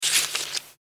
ボタン・システム （87件）
ページめくり1.mp3